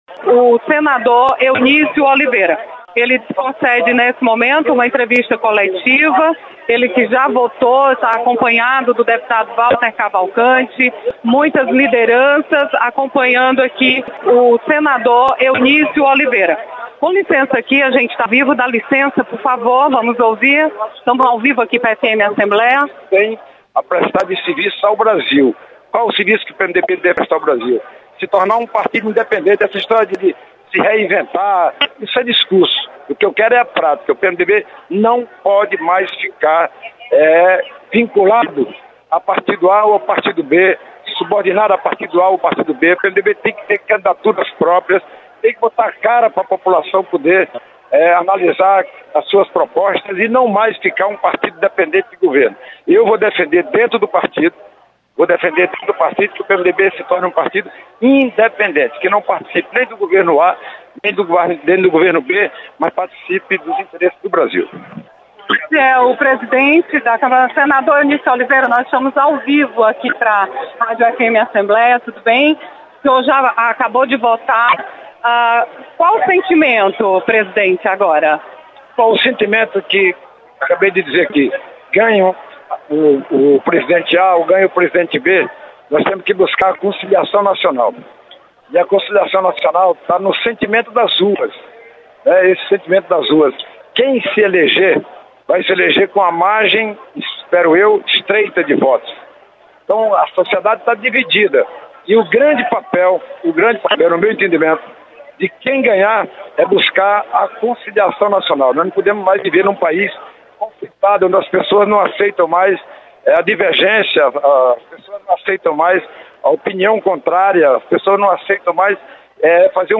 Senador Eunício Oliveira afirma que MDB terá novo papel na política brasileira. Repórter